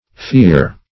pheer - definition of pheer - synonyms, pronunciation, spelling from Free Dictionary
pheer - definition of pheer - synonyms, pronunciation, spelling from Free Dictionary Search Result for " pheer" : The Collaborative International Dictionary of English v.0.48: Pheer \Pheer\ (f[=e]r), n. See 1st Fere .